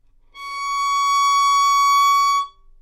单簧管单音 " 单簧管 Csharp6
描述：在巴塞罗那Universitat Pompeu Fabra音乐技术集团的goodsounds.org项目的背景下录制。
Tag: 好声音 单注 单簧管 多重采样 纽曼-U87 Csharp6